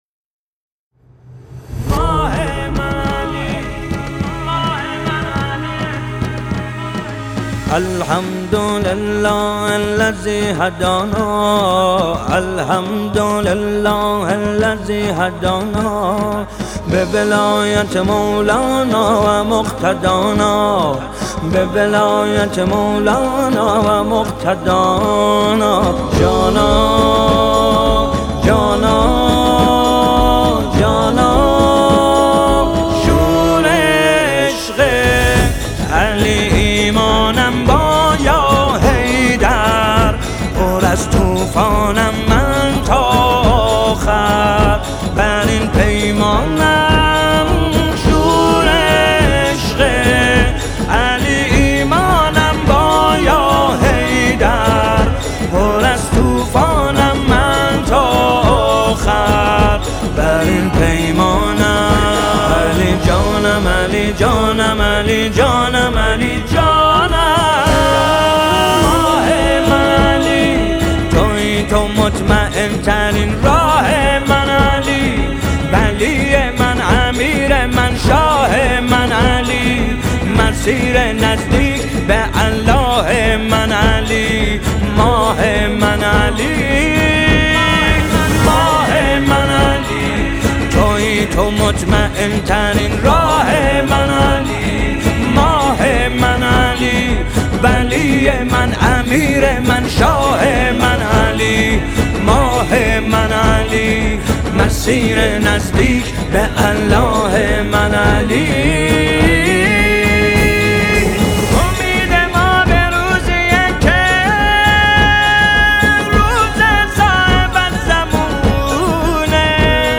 اثر استدیویی